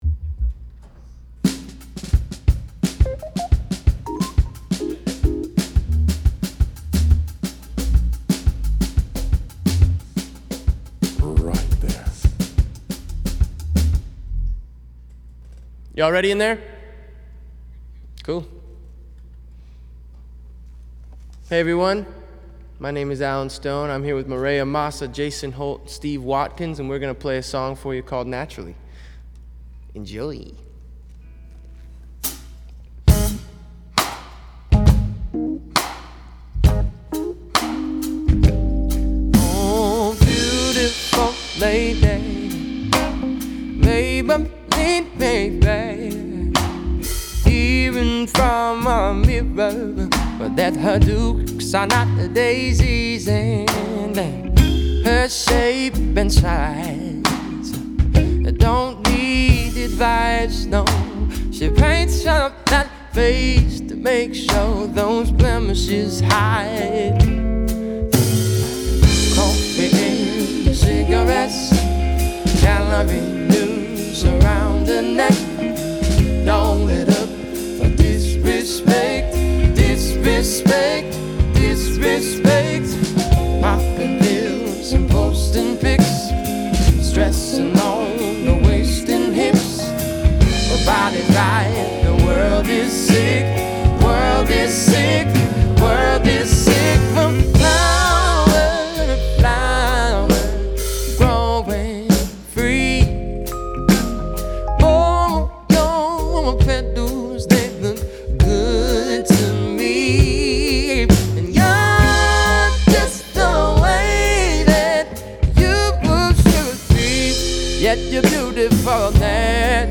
Совершенно приличный вокал по частотке
Из голоса не вычищал блиды кроме начала и бриджа